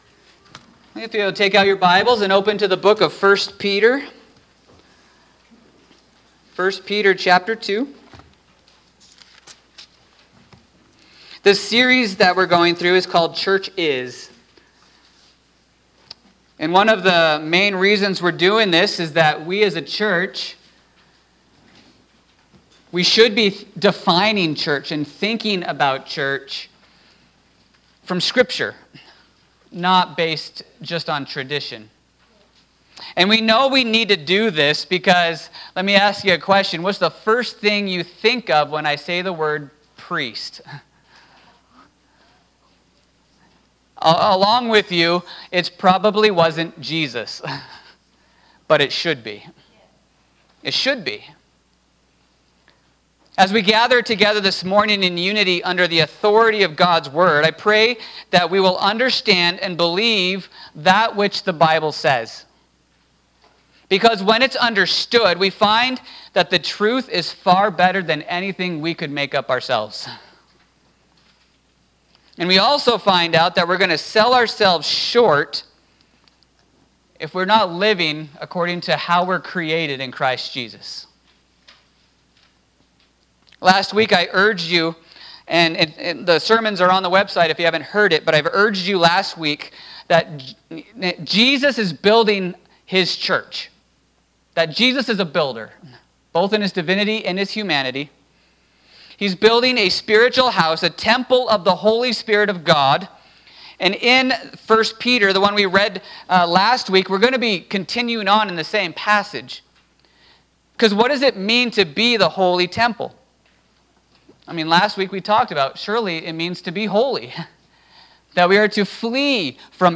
2024 Church Is…A Nation of Priests Preacher